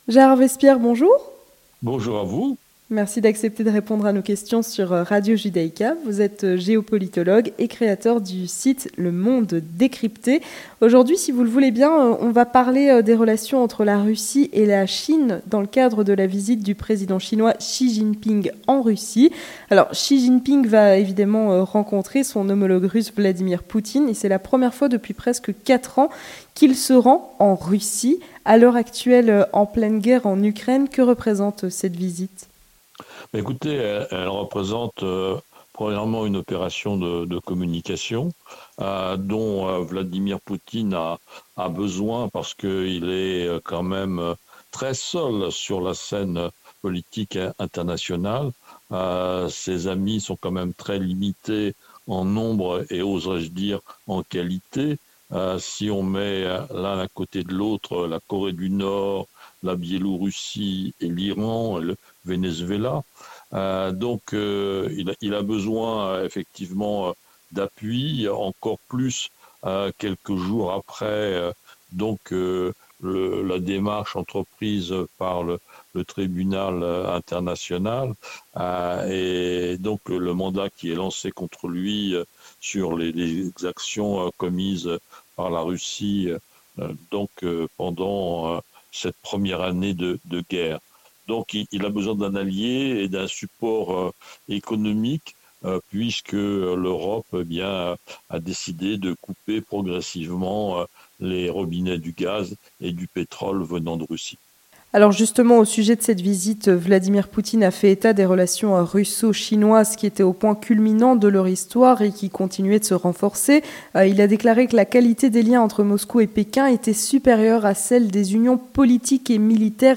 L'Entretien du 18h - La visite de Xi Jinping en Russie